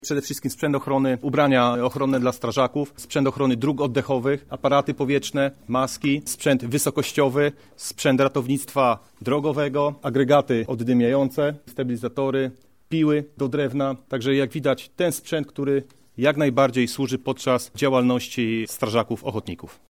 Sprzęt uzupełni braki w poszczególnych jednostkach, zastąpi też stare zużyte już rzeczy i urządzenia – mówi komendant powiatowy PSP powiatu łódzkiego wschodniego Krzysztof Supera.